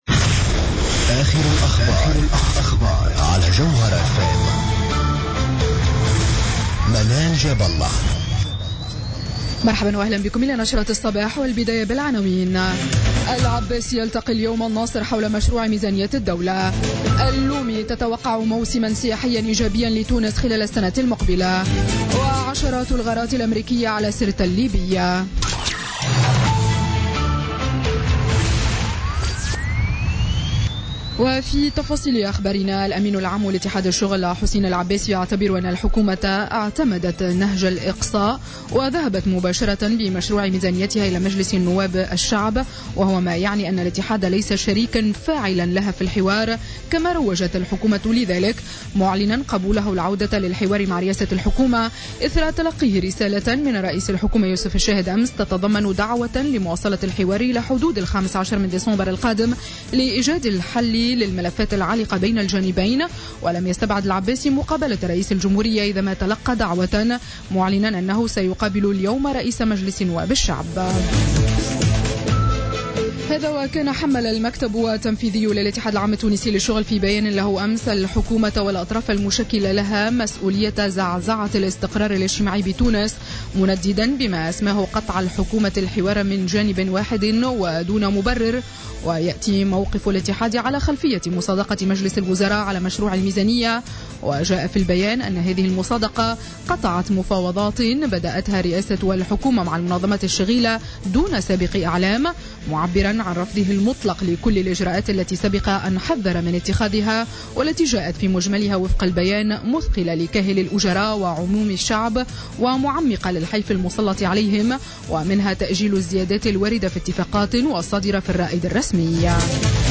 نشرة أخبار السابعة صباحا ليوم الثلاثاء 18 أكتوبر 2016